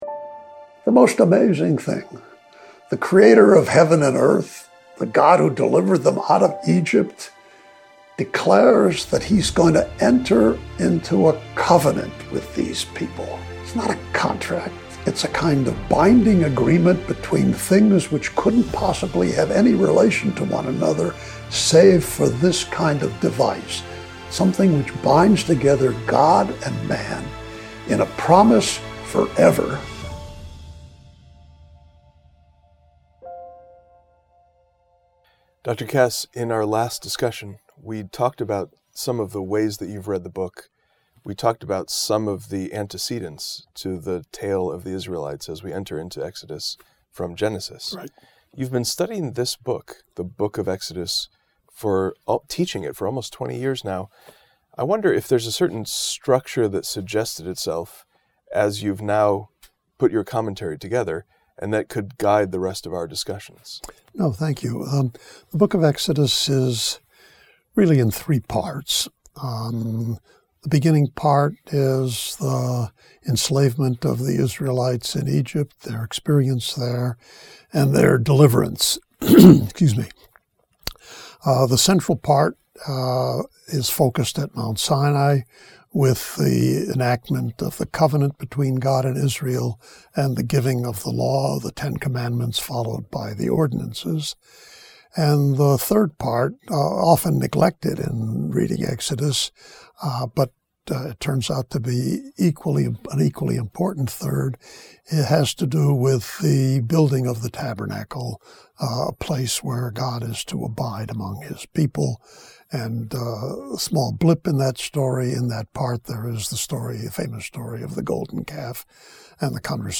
In this conversation, Professor Kass sets out the structure and frame of Exodus as a tale of national birth.